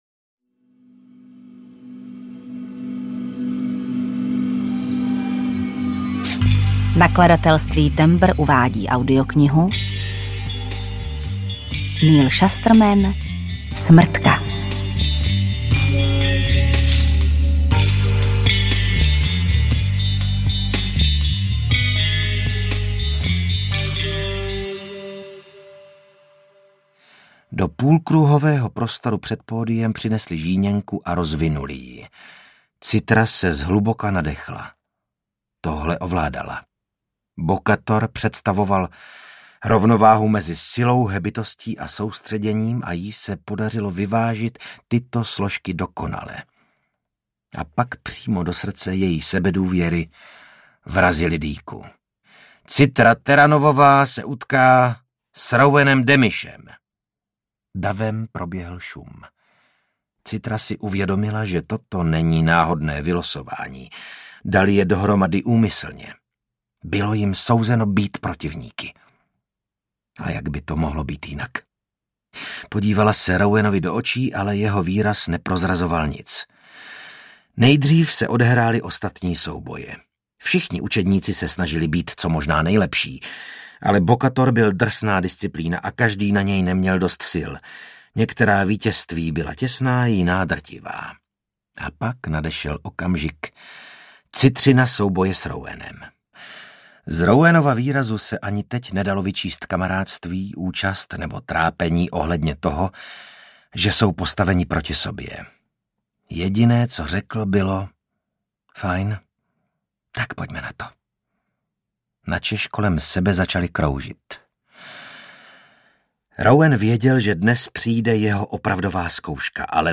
Smrtka audiokniha
Ukázka z knihy
• InterpretVasil Fridrich, Jana Stryková